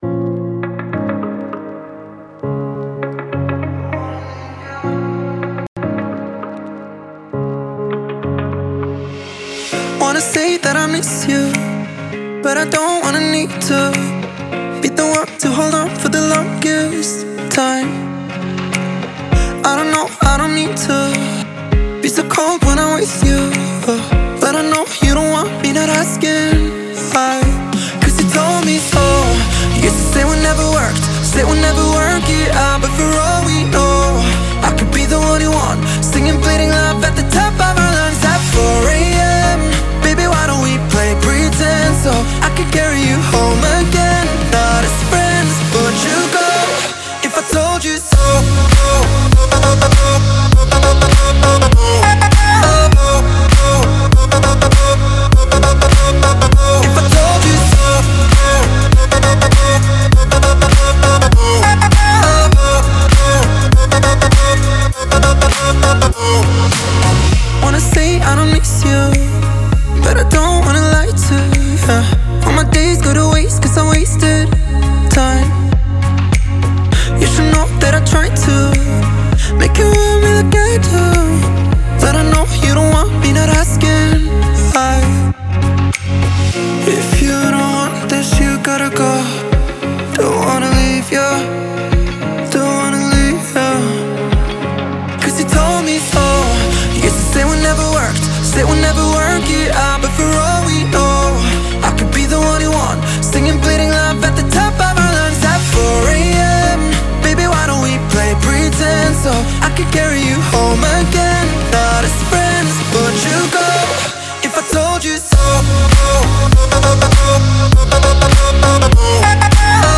это энергичная электронная танцевальная композиция